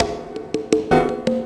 Percussion 08.wav